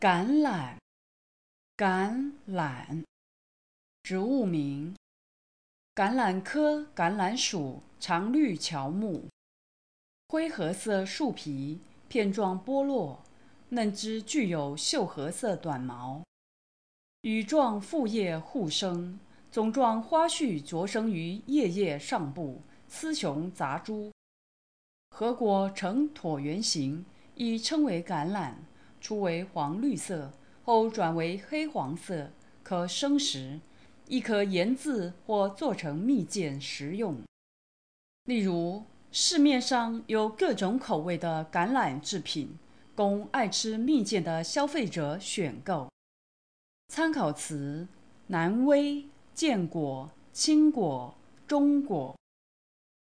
Advanced Information 【欖】 木 -21-25 Word 橄欖 Pronunciation ㄍㄢ ˇ ㄌㄢ ˇ ▶ Definition 植物名。